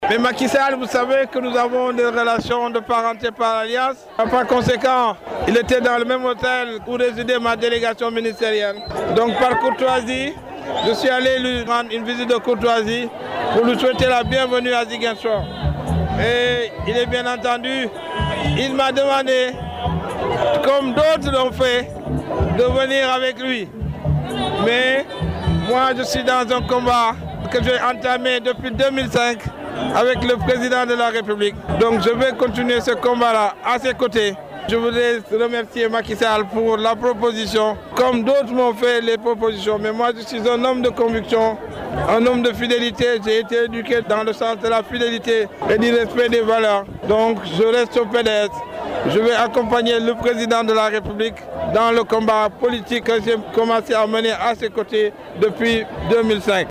Abdoulaye Balde réagit aux informations rapportées par la presse lors de la conférence de presse du candidat Macky Sall…